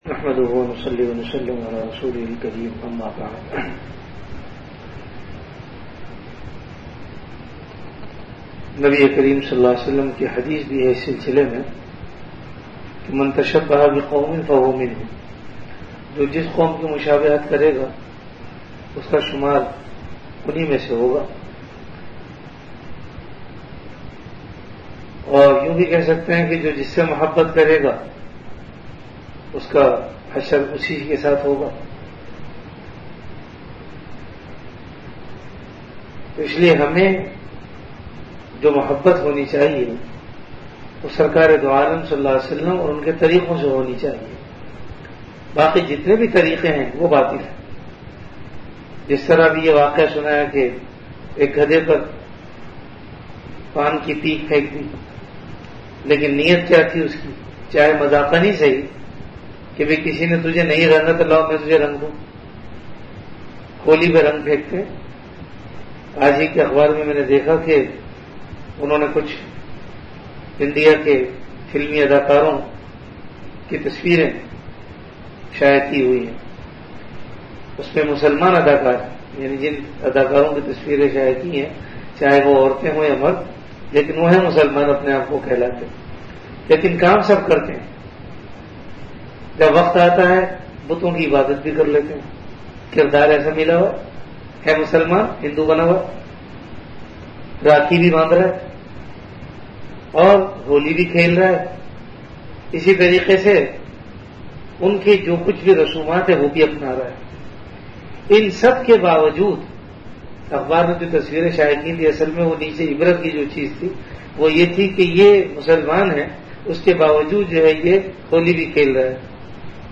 Category Majlis-e-Zikr
Event / Time After Isha Prayer